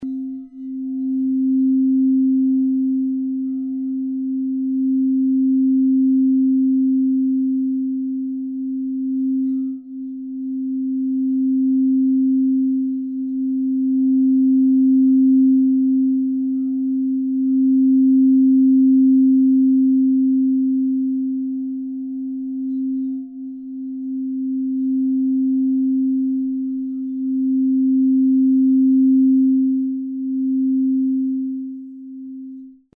Quarzkristall-Klangschale ca. 30 cm Durchmesser mit Klöppel
Das mächtige, lang schwingende Klangvolumen dieser Klangschalen aus Quarzkristall füllt selbst große Räume mit einem klaren, reinen Klang.
Bei den Klangbeispielen kommt der obere Klang von einer angeschlagenen Schale und der untere von einer angeriebenen. Beide Tone sind Klangbeispiele einer vergleichbaren Kristallschale mit 30 cm Durchmesser
• klar und rein
• mächtiger Ton
• lang schwingendes Klangvolumen
• sachtes Anreiben am oberen Rand
KL-QU-30-reiben.mp3